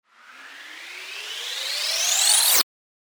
FX-808-RISER
FX-808-RISER.mp3